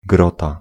Ääntäminen
Synonyymit jaskinia pieczara Ääntäminen Tuntematon aksentti: IPA: /ˈɡrɔta/ Haettu sana löytyi näillä lähdekielillä: puola Käännös Ääninäyte Substantiivit 1. grotto US Suku: f .